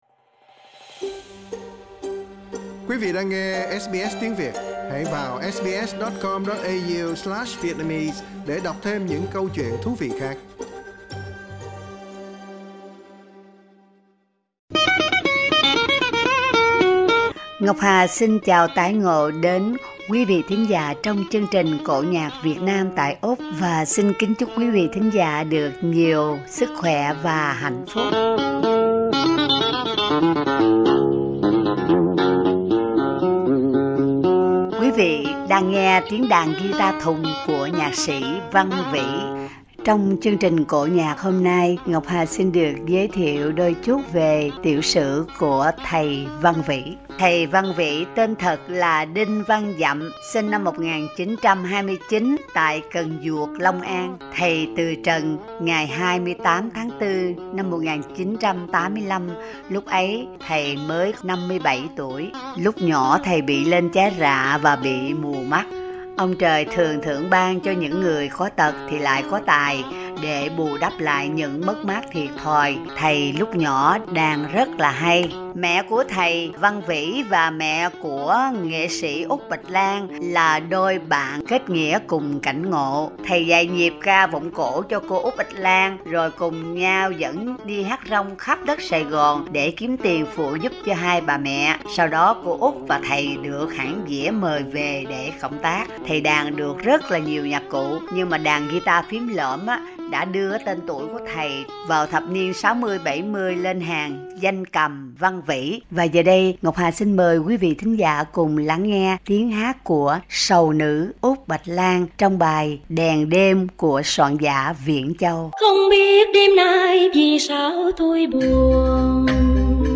cổ nhạc miền Nam
chiếc đàn ghi ta phím lõm